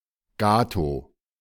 Gatow (German: [ˈɡaːtoː]
De-Gatow.ogg.mp3